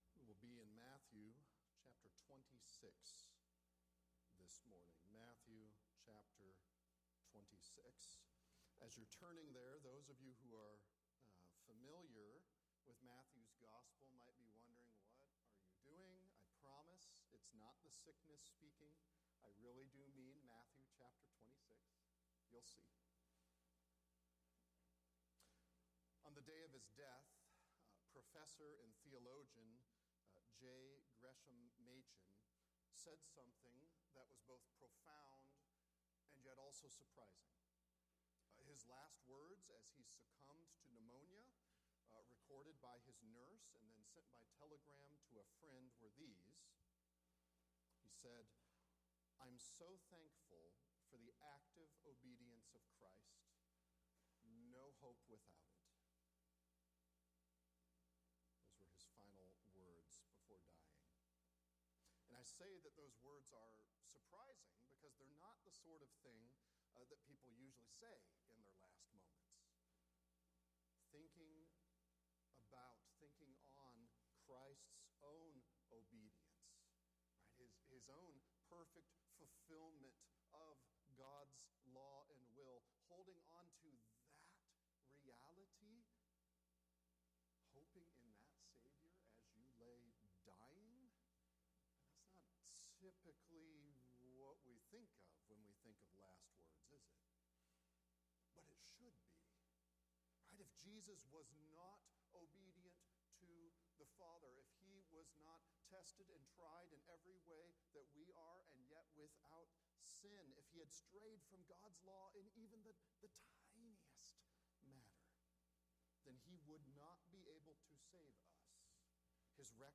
Matthew 26:36-46 Our Obedient God – Sermons